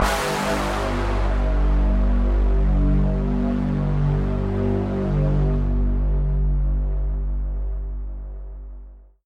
Звуковой эффект появления титров Game over